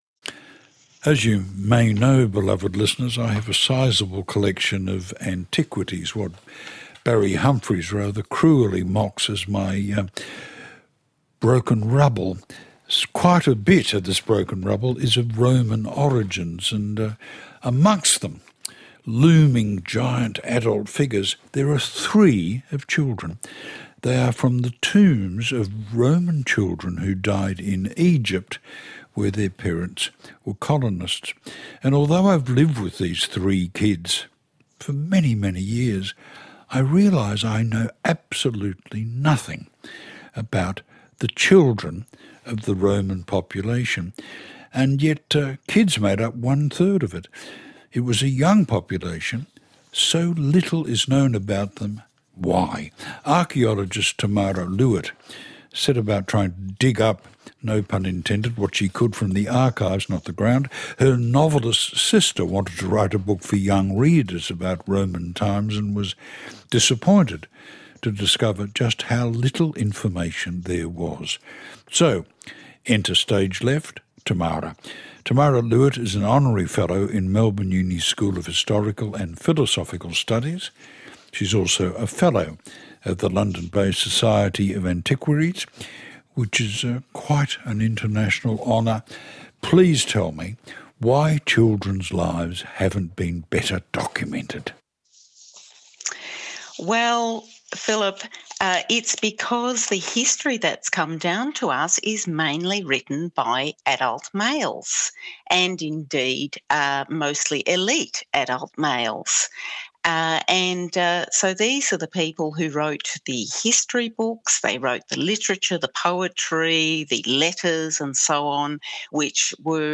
interview with Philip Adams on ABC Radio Late Night Live